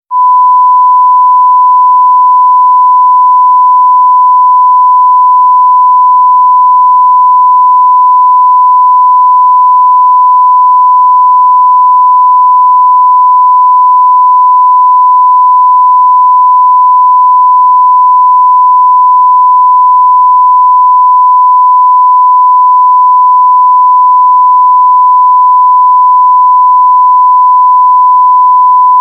P O L I C E
pitido_24719.mp3